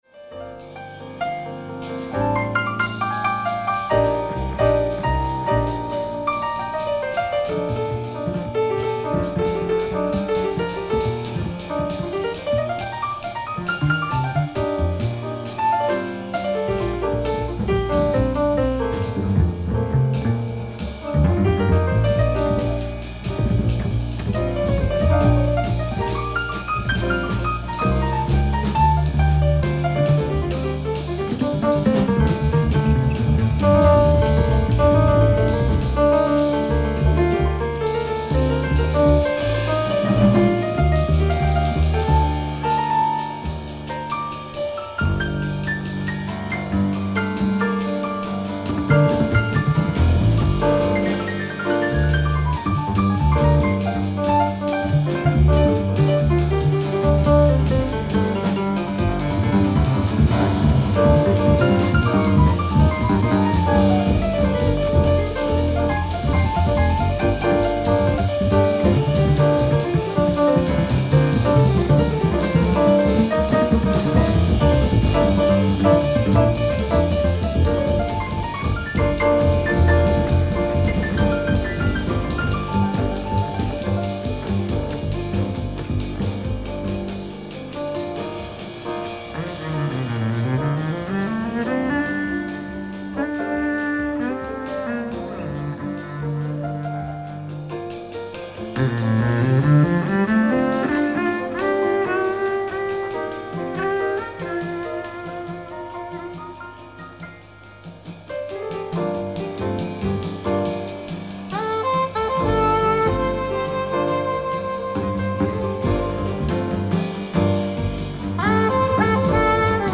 ライブ・アット・ハンブルグ、ドイツ
※試聴用に実際より音質を落としています。